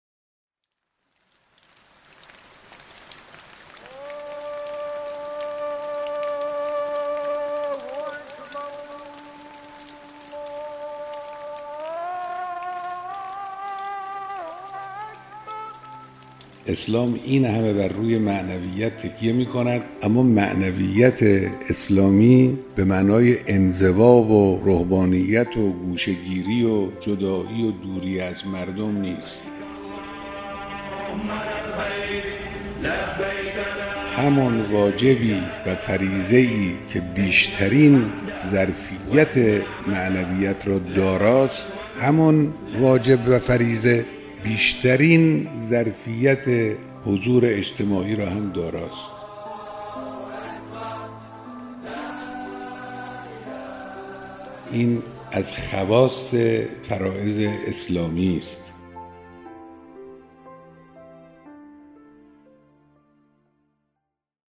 رهبر معظم انقلاب در یکی از سخنرانی‌های خود درباره معنویت اسلام سخن گفتند و فرمودند: اسلام این همه بر روی معنویت تکیه می‌کند، اما معنویت اسلامی به معنای انزوا و رهبانیت و گوشه‌گیری و جدایی و دوری از مردم نیست.